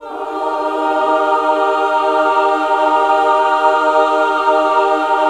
Index of /90_sSampleCDs/USB Soundscan vol.28 - Choir Acoustic & Synth [AKAI] 1CD/Partition B/06-MENWO CHD